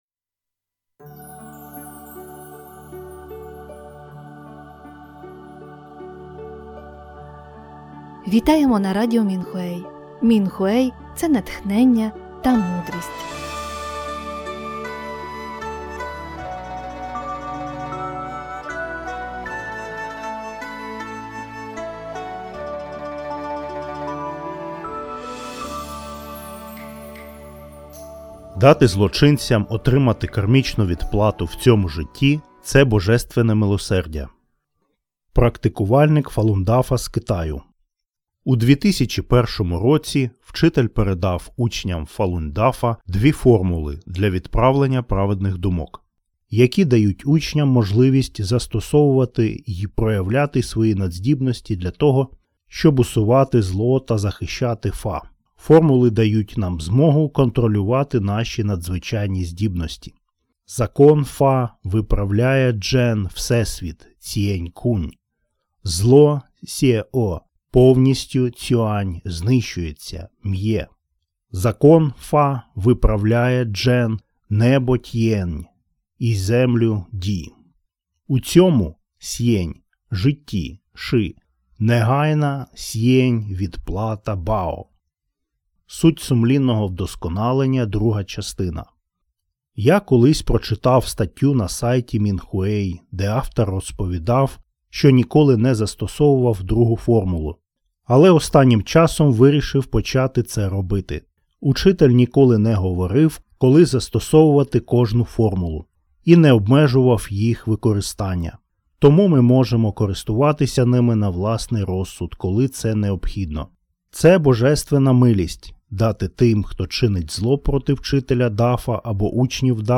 Музика з подкастів написана та виконана учнями Фалунь Дафа.